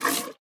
哞菇：被挤奶
玩家使用碗对哞菇挤奶时随机播放这些音效
Minecraft_mooshroom_milk1.mp3